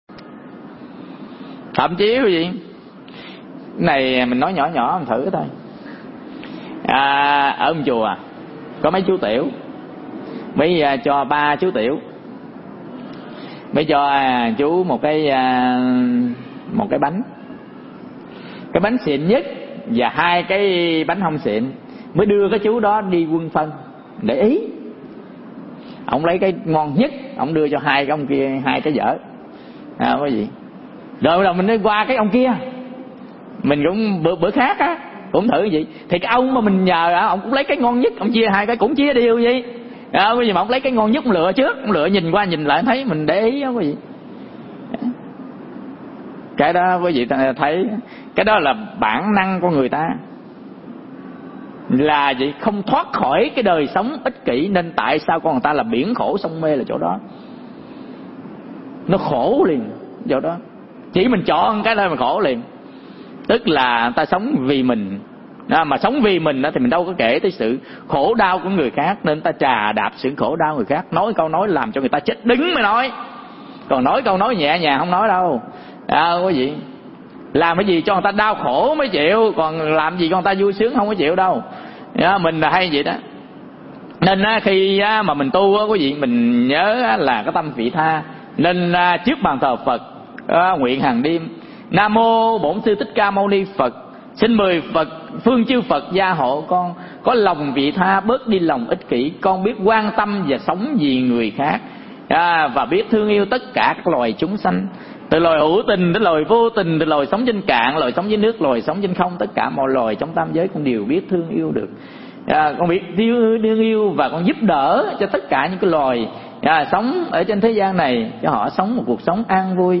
Mp3 Pháp Thoại Tâm Vị Tha 2